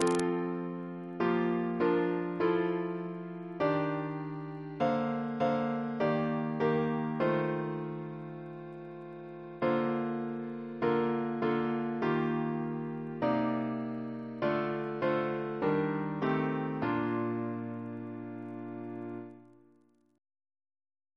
Double chant in B♭ Composer